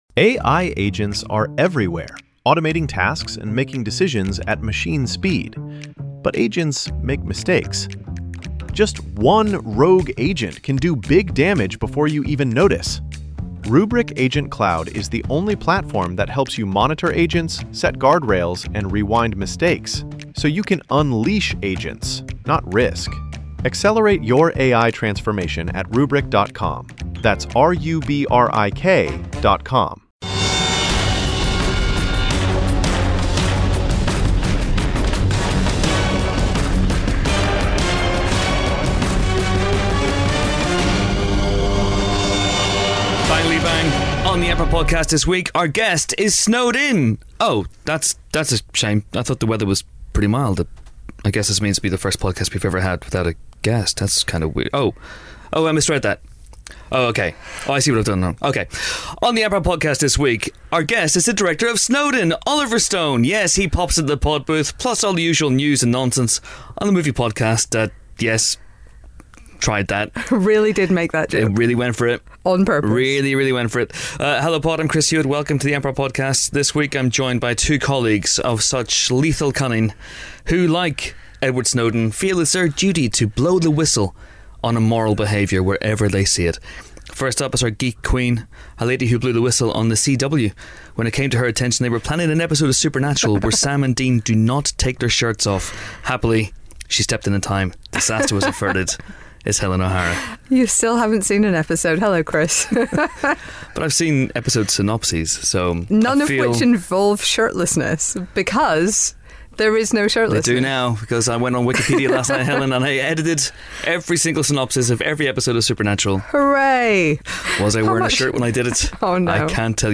#241: Oliver Stone The Empire Film Podcast Bauer Media Tv & Film, Film Reviews 4.6 • 2.7K Ratings 🗓 9 December 2016 ⏱ 64 minutes 🔗 Recording | iTunes | RSS 🧾 Download transcript Summary This week, we hack into the mainframe to bring you an interview with Snowden director Oliver Stone. Plus, the team discuss whether "woke bae" is the same as "Wookiee bae".